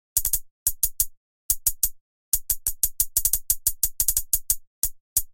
Tag: 90 bpm Trap Loops Percussion Loops 918.79 KB wav Key : Unknown